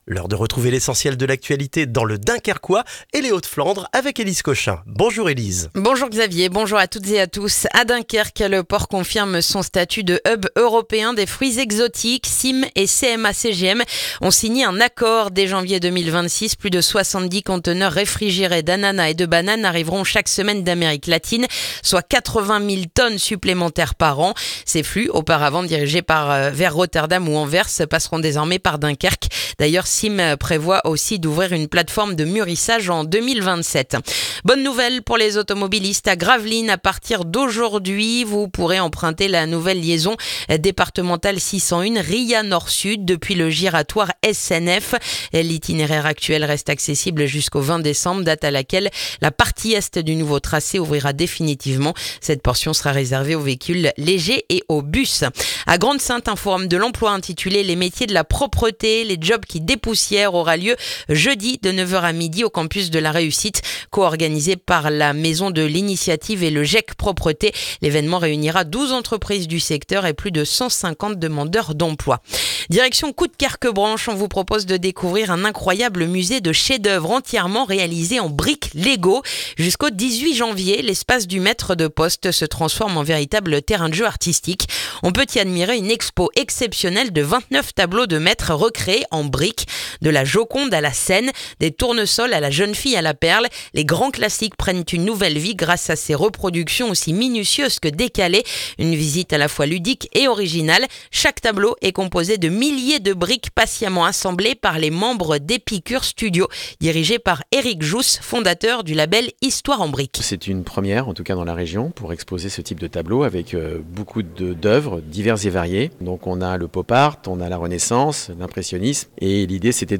Le journal du mardi 2 décembre dans le dunkerquois